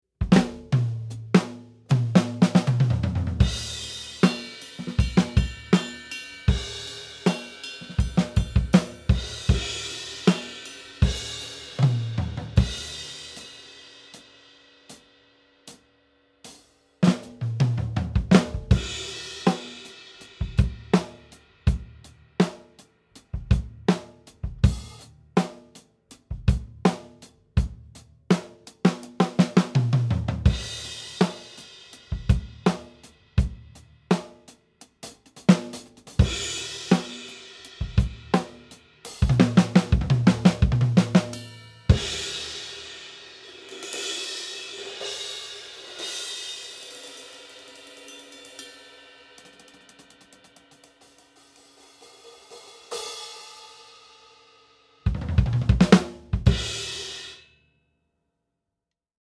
Rummut: soittaminen
Sain hankittua rumpunurkkauksen ja muun olkkarin väliin paksut molton-verhot ja näin muodoin soittotilan akustiikka parani - lisäksi kuivan lähisoundin ja verhojen toisella puolella olevien tilamikkien välistä balanssia on nyt vaivatonta kontrolloida. Ekaa pikatestiä sen kummemmin ruuvaamatta: